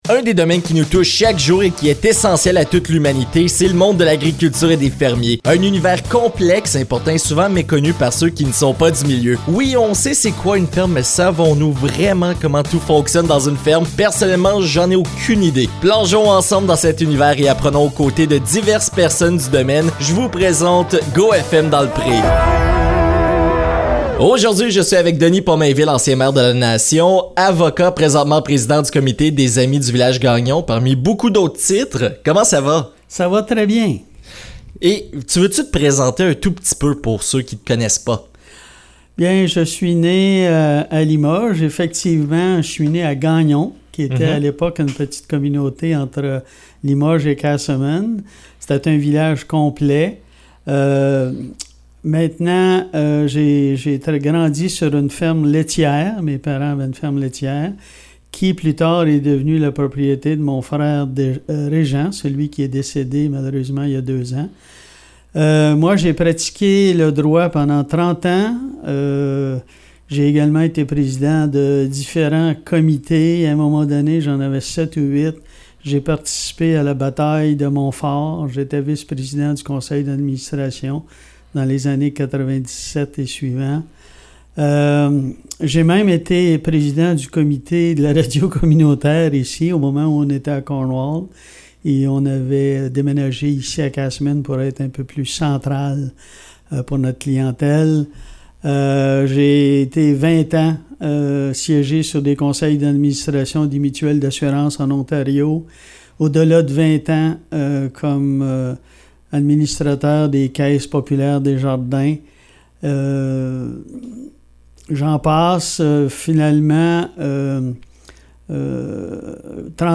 Dans cette épisode nous allons discuter avec Denis Pommainville, l'ancien maire de la Nation.